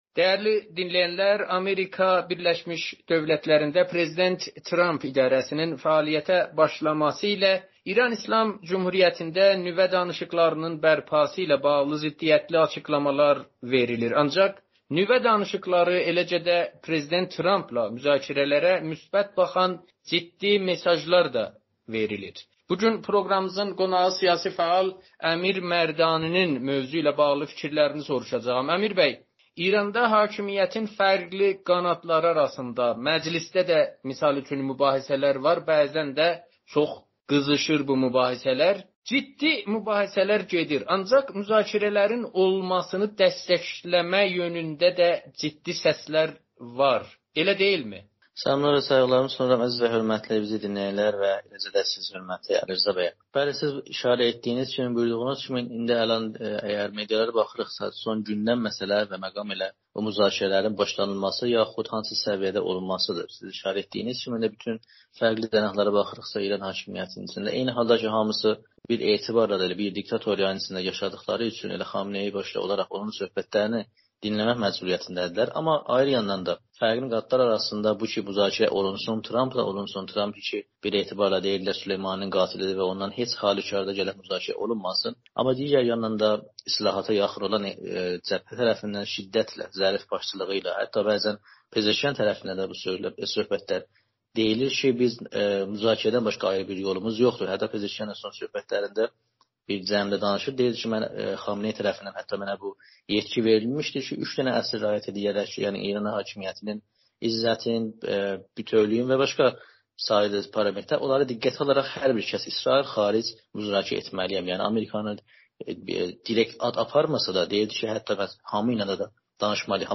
Amerikanın Səsi ilə söhbətində